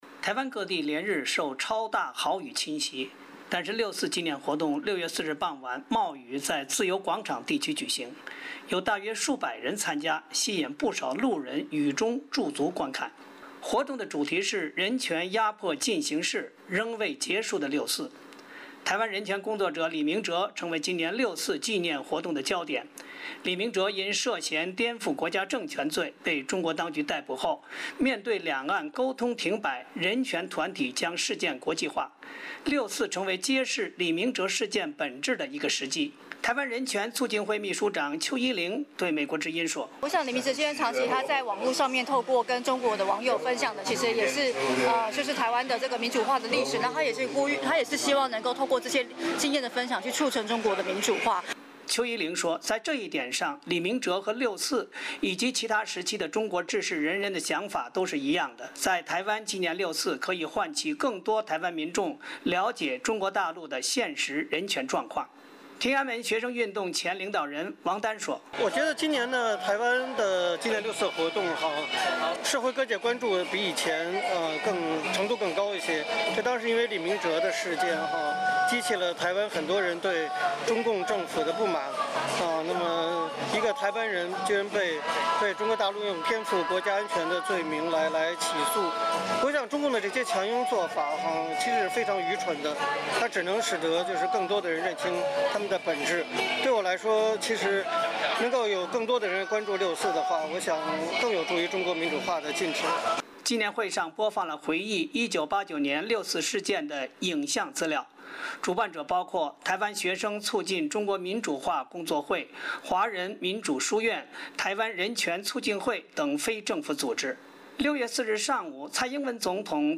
台湾各地连日受超大豪雨侵袭，但是六四纪念活动6月4日傍晚冒雨在自由广场地区举行，有大约数百人参加，吸引不少路人雨中驻足观看。